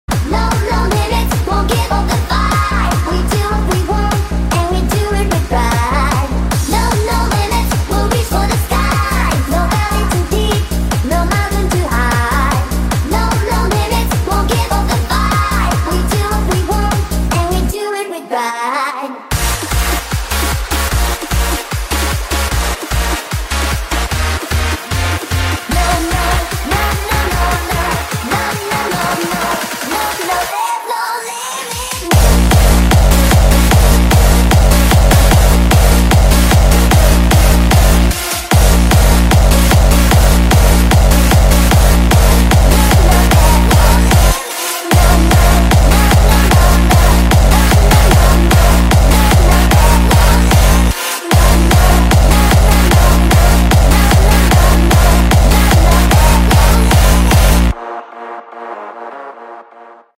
Hardstyle Remix